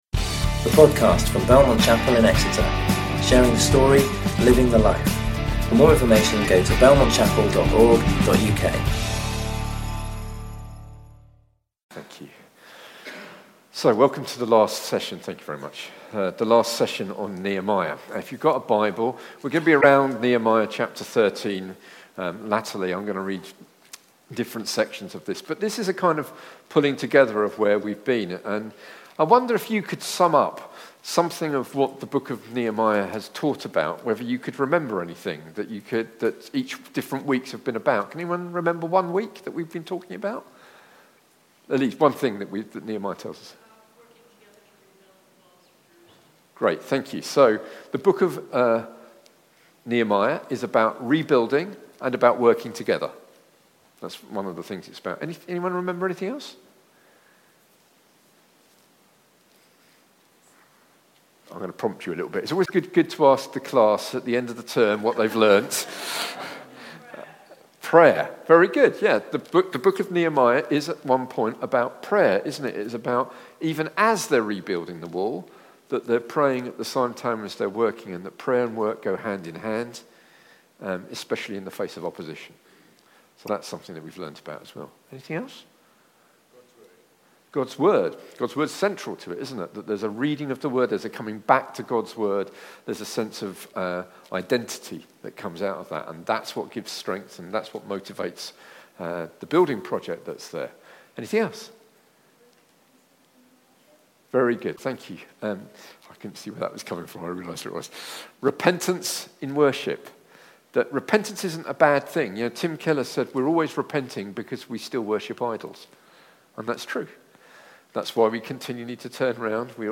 Belmont Exeter's weekly morning and evening service talks.